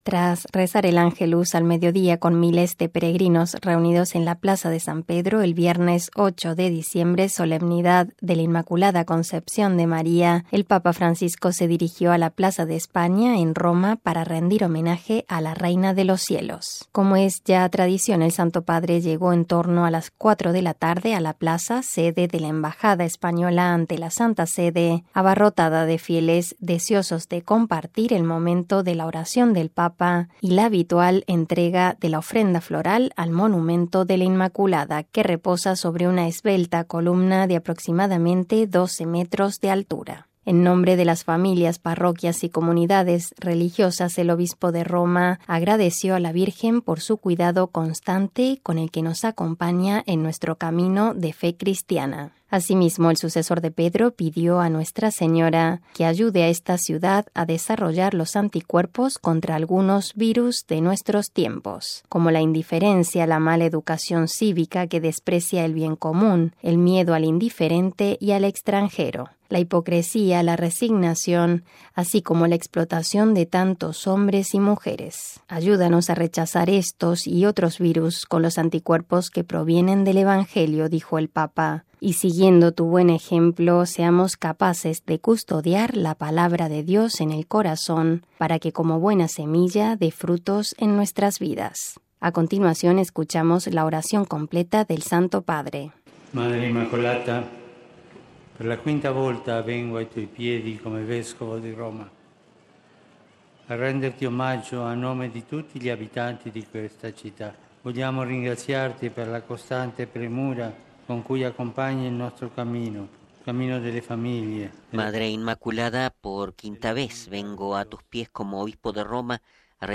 Como es ya tradición, el Santo Padre llegó en torno a las 4:00 de la tarde a la plaza, sede de la embajada española ante la Santa Sede, abarrotada de fieles deseosos de compartir el momento de la oración del Papa y la entrega de la ofrenda floral al monumento de la Inmaculada, que reposa sobre una esbelta columna de aproximadamente 12 metros de altura.